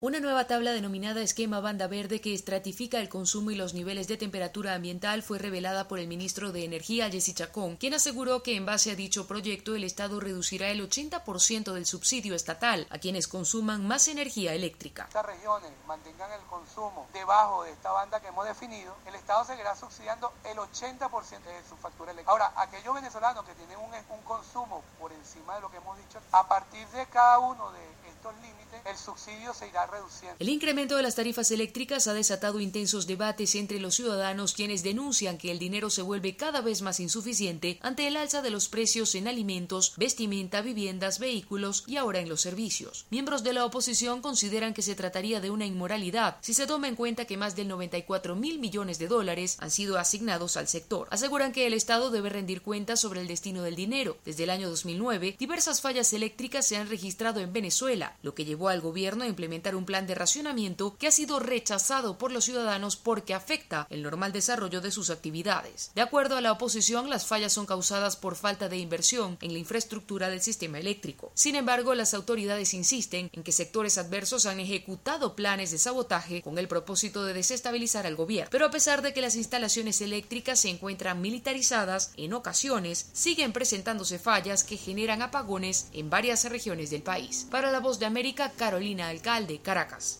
Una nueva tabla denominada “esquema banda verde”, llega con el anuncio de que el gobierno venezolano seguirá subsidiando el costo de energía eléctrica solo para quienes mantengan el uso por debajo de ella. Desde Caracas informa